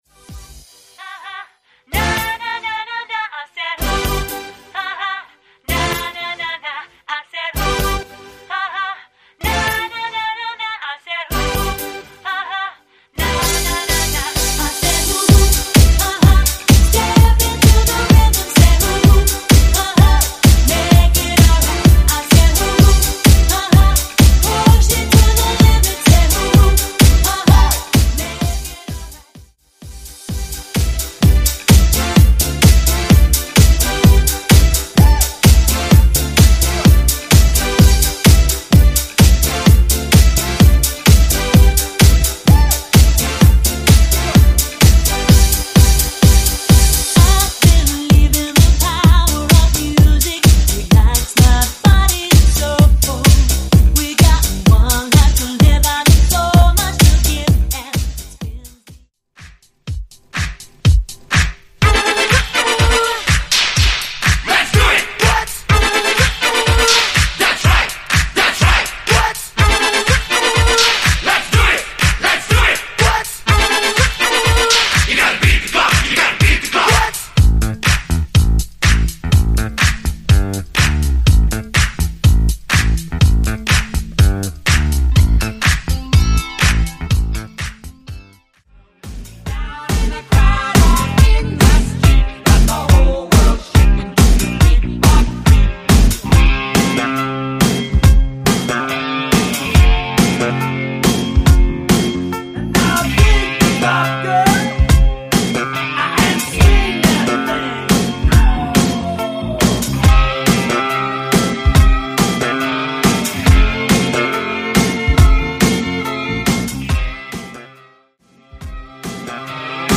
Genre: TOP40
Clean BPM: 125 Time